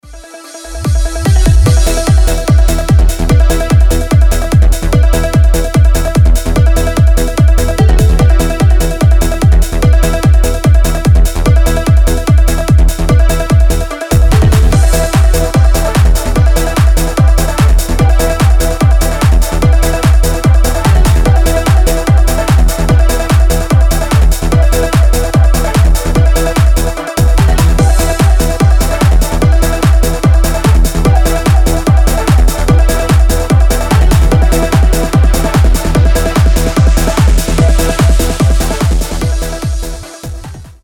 • Качество: 320, Stereo
Electronic
EDM
электронная музыка
без слов
Uplifting trance